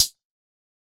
004_Hi-Hat_Bumper.wav